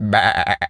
sheep_baa_bleat_03.wav